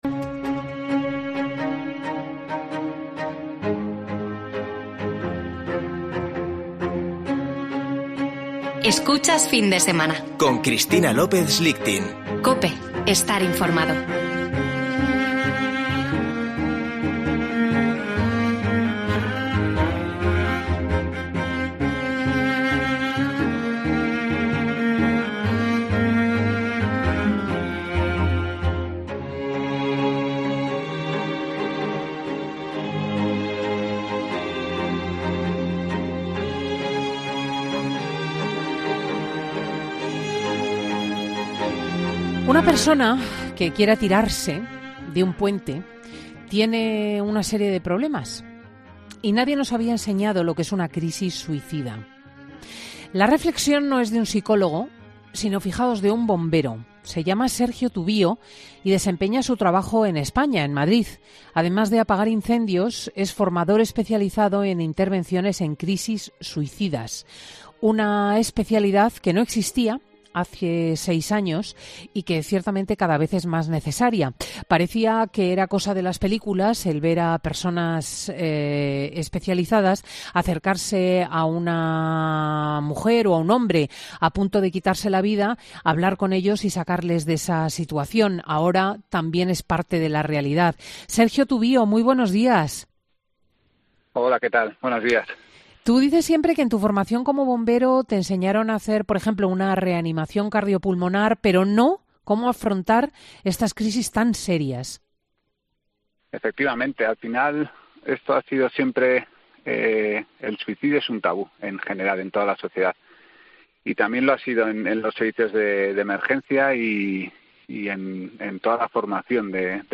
Esta reflexión no es de un psicólogo sino de un bombero.